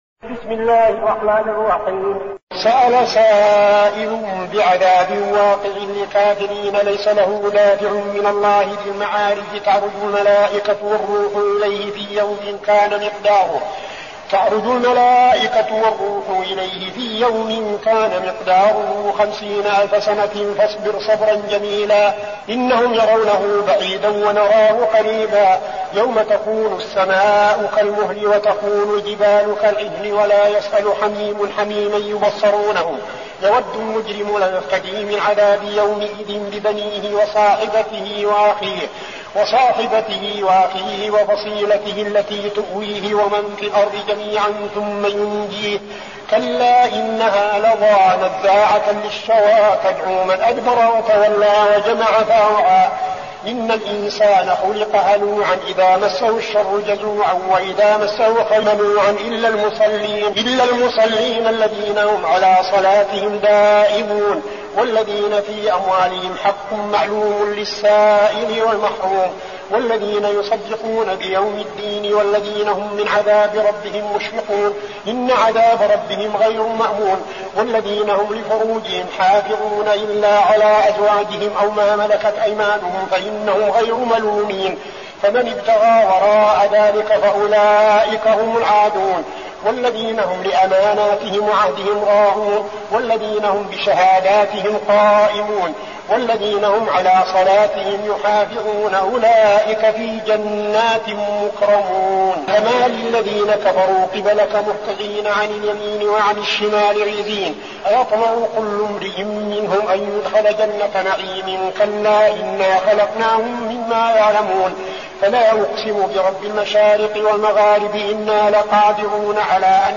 المكان: المسجد النبوي الشيخ: فضيلة الشيخ عبدالعزيز بن صالح فضيلة الشيخ عبدالعزيز بن صالح المعارج